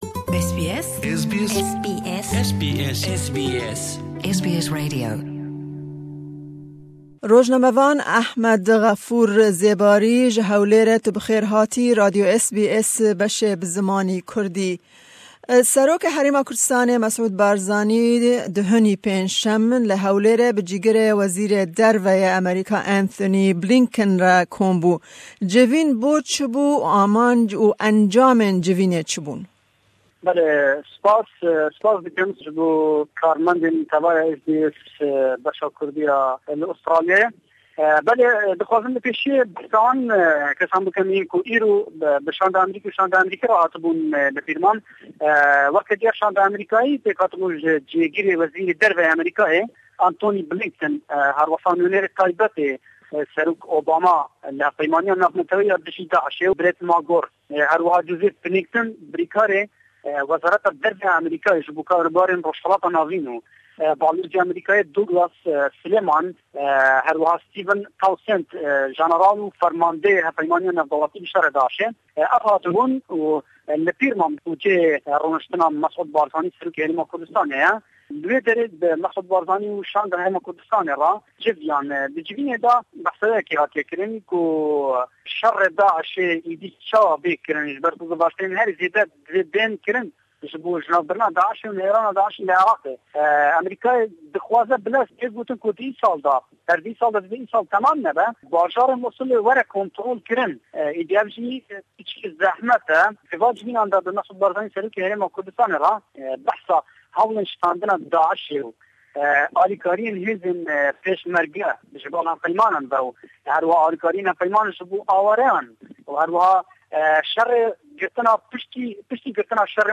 Me hevpeyvînek